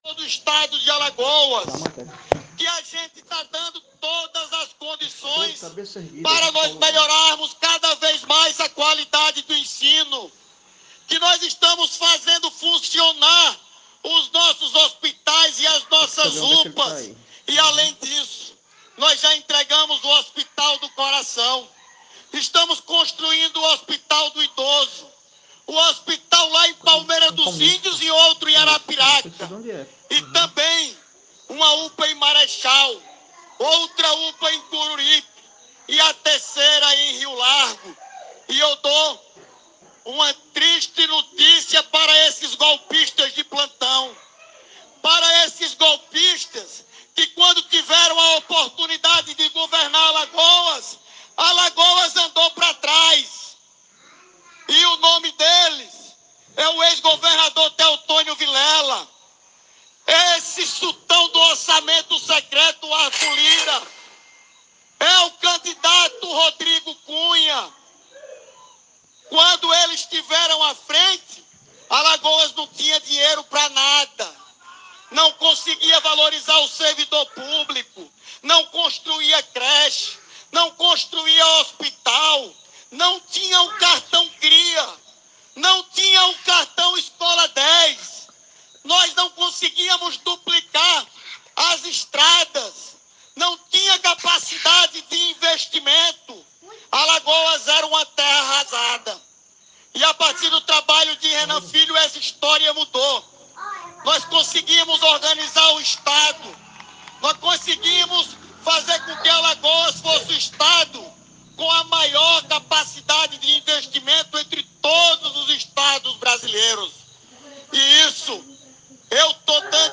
Durante seu discurso em comício em Maceió, candidato à reeleição desabafou após a decisão do STJ que o afastou do cargo de governador